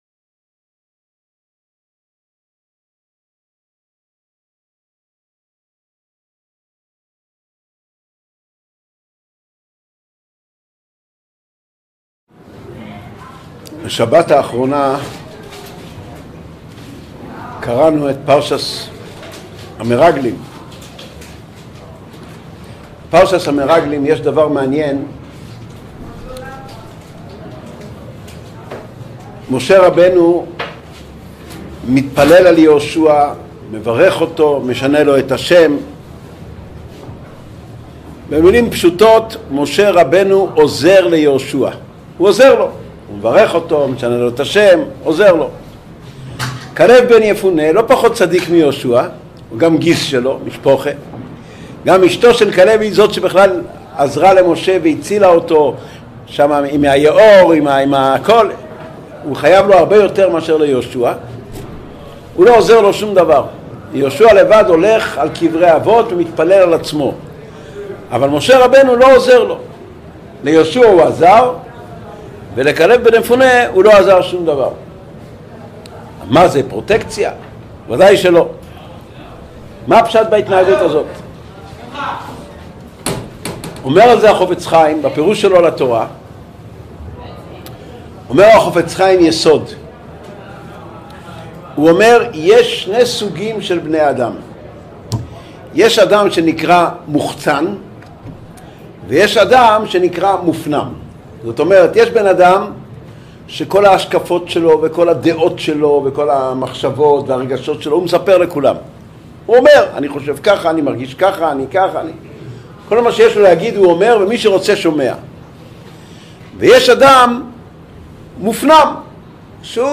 Урок № 2. Характер ребенка. Содержание урока: Два типа людей: интроверт и экстраверт.